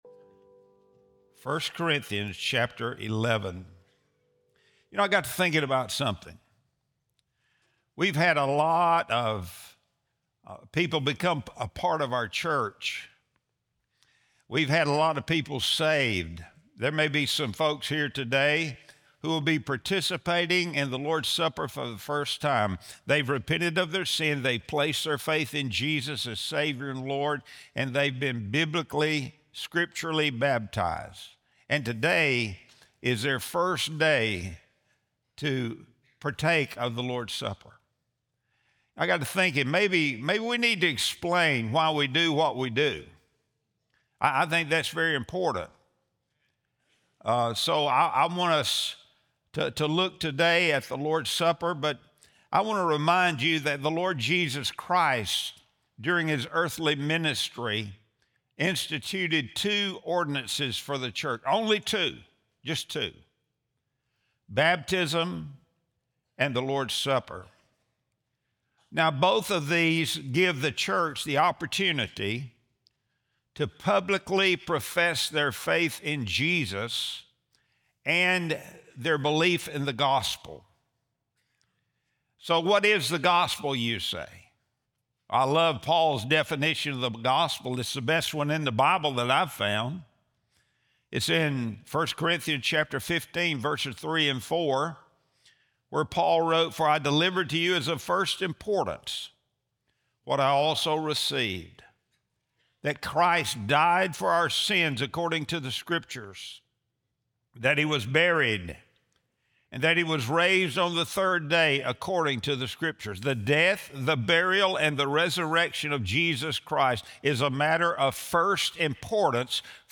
Sunday Sermon | November 30, 2025